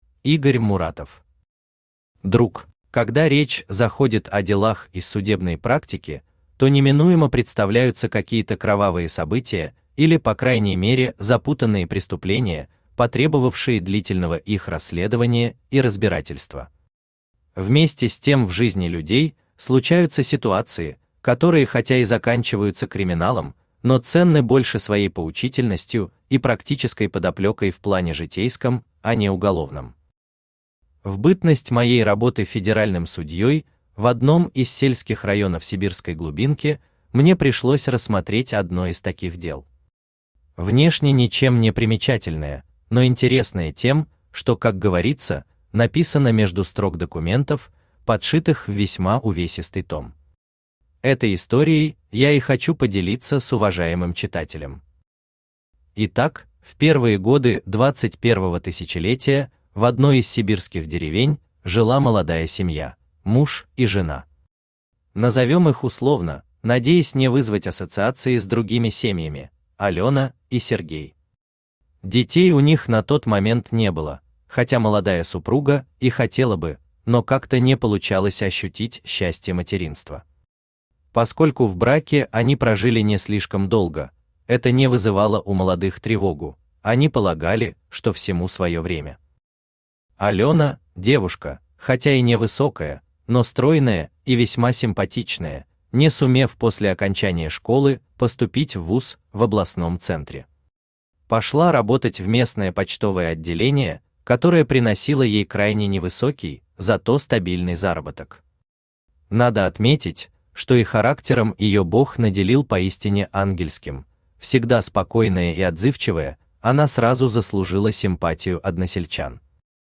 Аудиокнига Друг | Библиотека аудиокниг
Aудиокнига Друг Автор Игорь Муратов Читает аудиокнигу Авточтец ЛитРес.